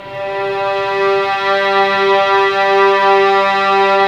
Index of /90_sSampleCDs/Roland L-CD702/VOL-1/STR_Vlns 6 mf-f/STR_Vls6 mf amb